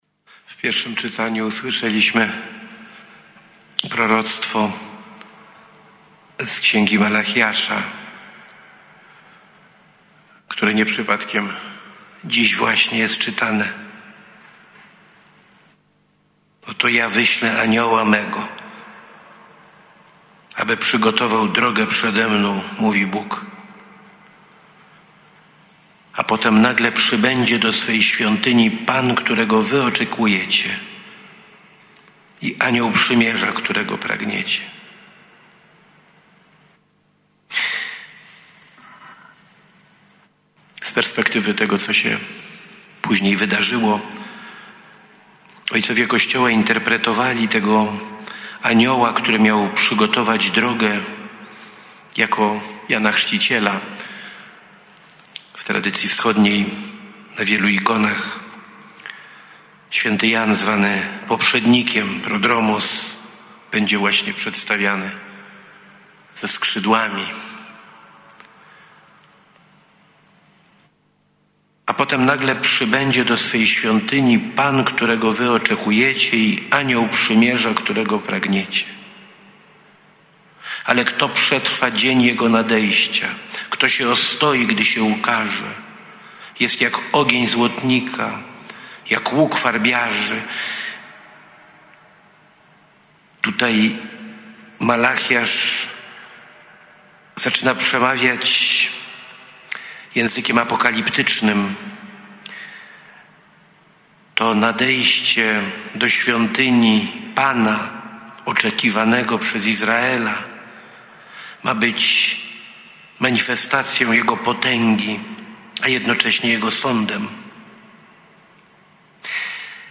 W Święto Ofiarowania Pańskiego, które jest także Dniem Życia Konsekrowanego, Eucharystii w naszym kościele przewodniczył J.E. Ks. Bp Michał Janocha, który następnie spotkał się ze wspólnotą.
Homilia Ks. Bp. Michała Janochy: